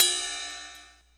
ride.wav